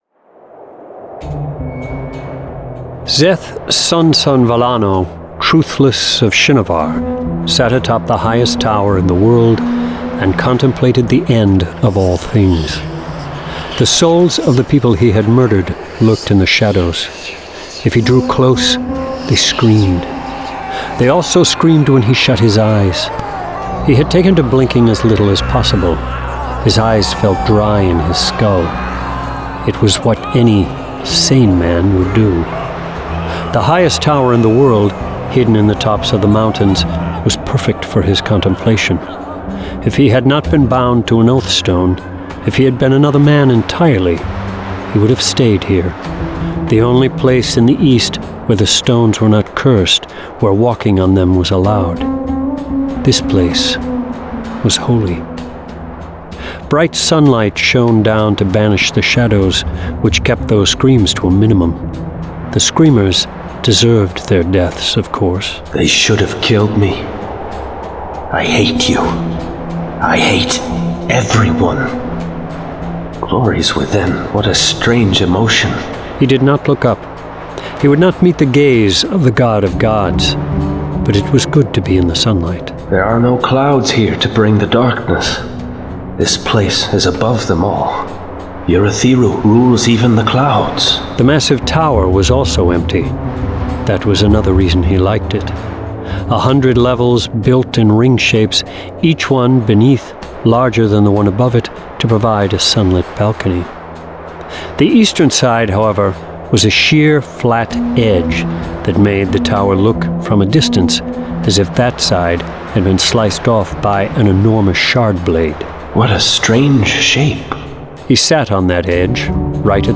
Full Cast. Cinematic Music. Sound Effects.
[Dramatized Adaptation]
Genre: Fantasy